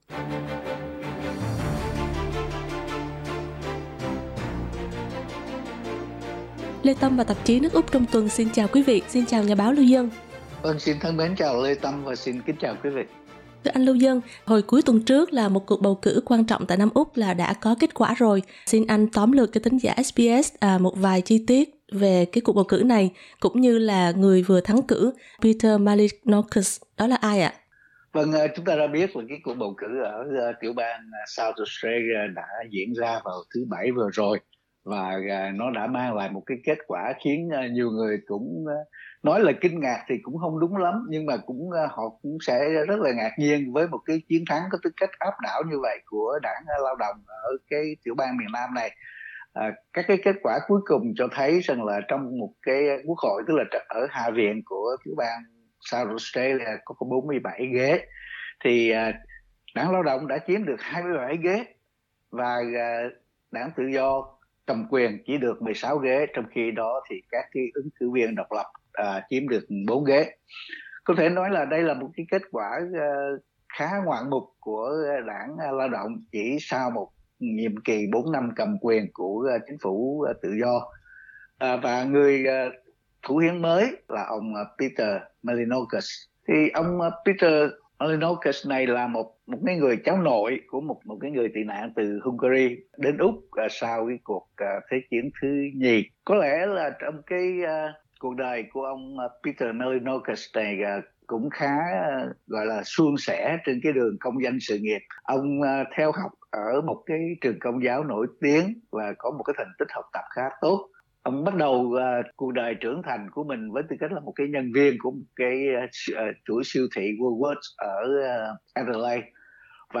bài bình luận